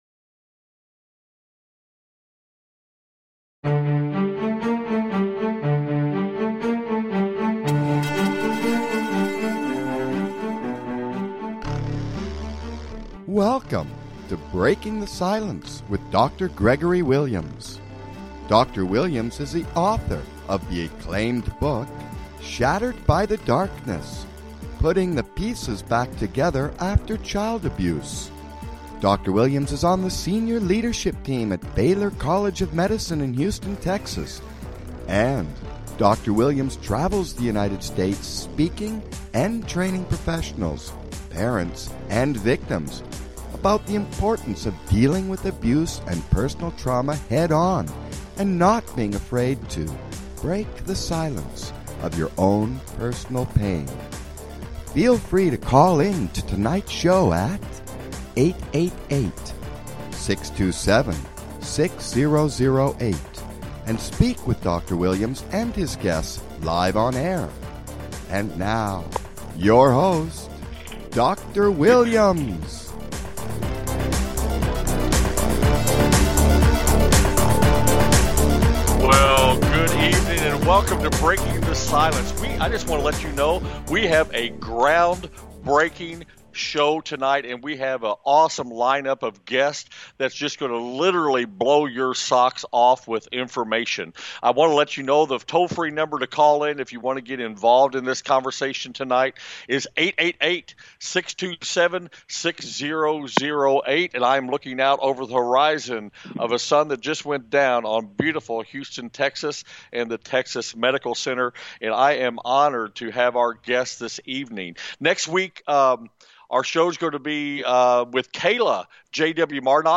Talk Show Episode